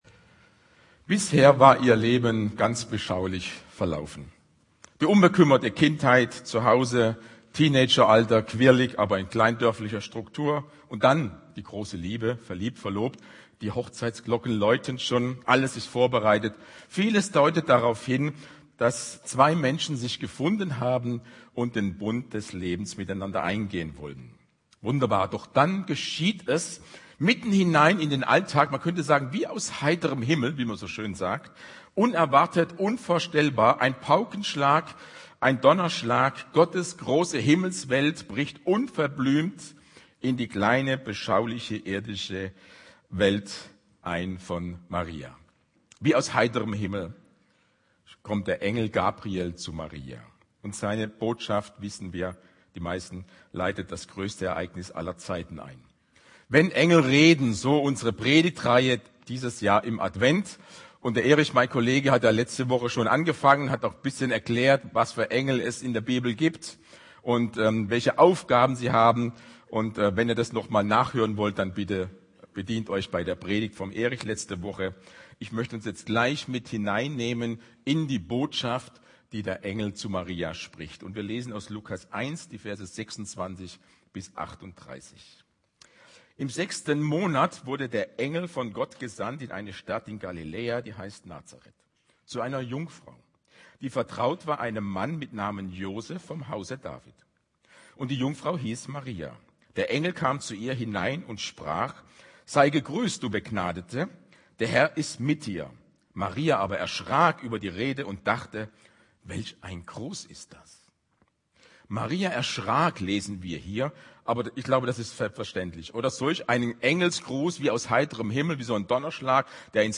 Predigt Wenn Engel reden Evangelien